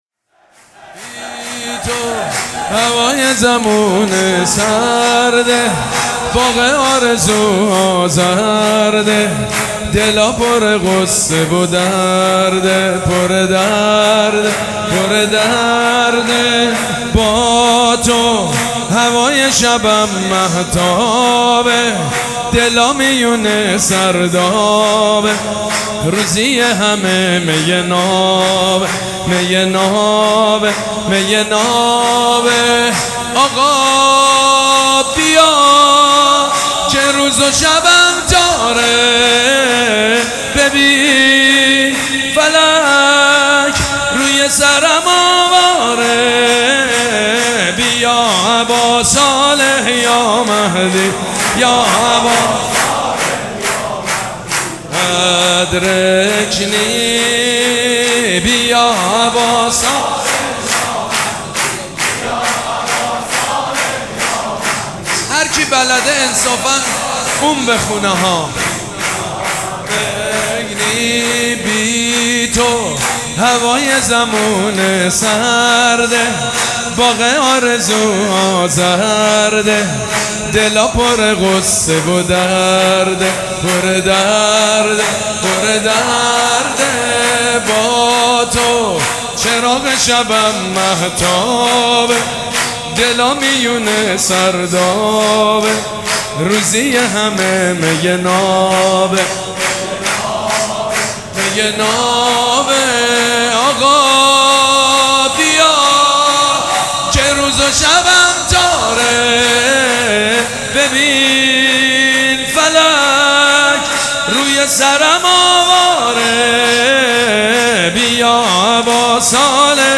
تولید شده: ریحانه الحسین سیدمجید بنی فاطمه برچسب‌هامداحی میلاد امام زمان میلاد امام زمان